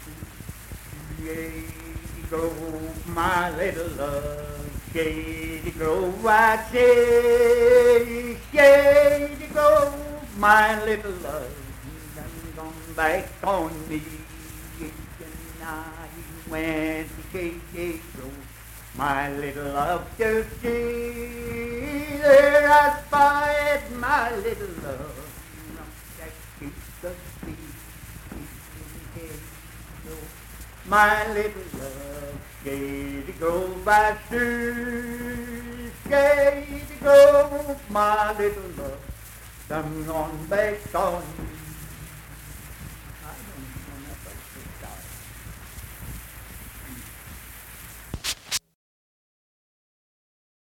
Shady Grove - West Virginia Folk Music | WVU Libraries
Unaccompanied vocal music performance
Voice (sung)